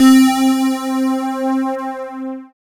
Index of /90_sSampleCDs/Sound & Vision - Gigapack I CD 2 (Roland)/SYN_ANALOG 1/SYN_Analog 2
SYN BELLST01.wav